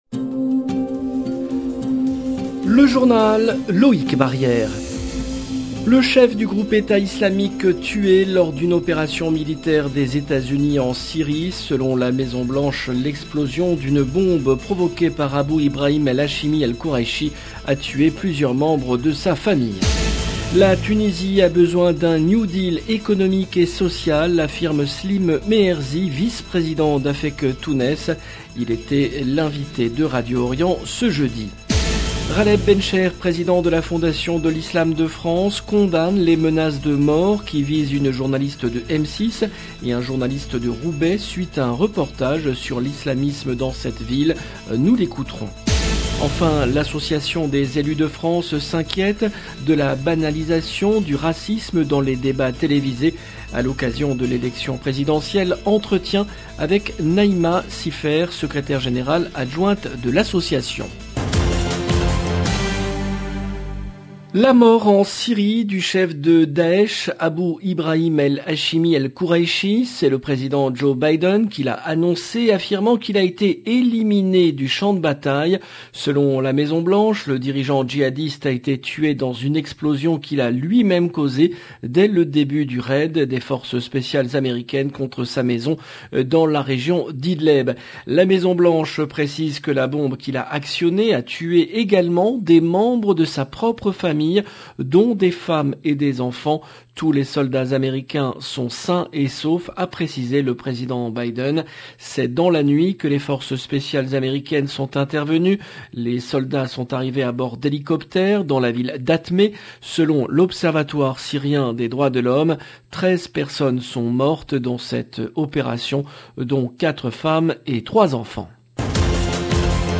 JOURNAL EN LANGUE FRANÇAISE